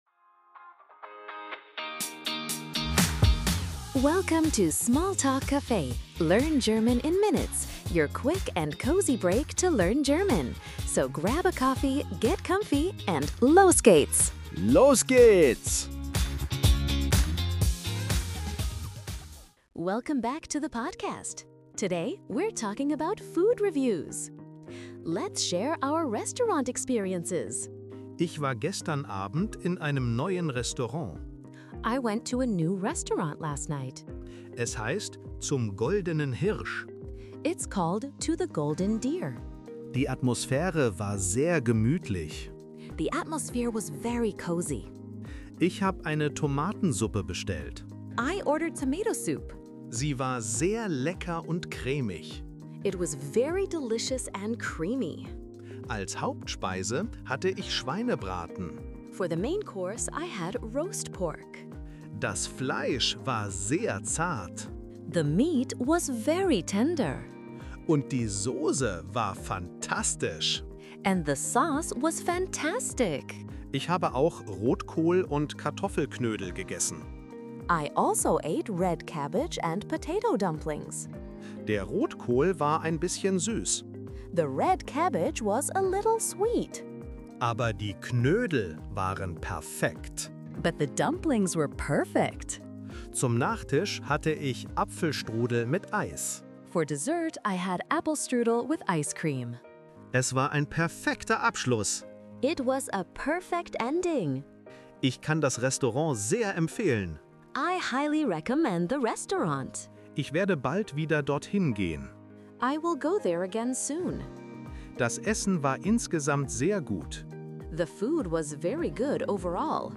Tune in for quick, real-life dialogues, helpful tips, and the confidence boost you need to navigate daily errands in German!